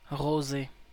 Ääntäminen
Synonyymit vin rosé rosâtre Ääntäminen CAN Tuntematon aksentti: IPA: /ʁo.ze/ Haettu sana löytyi näillä lähdekielillä: ranska Käännöksiä ei löytynyt valitulle kohdekielelle.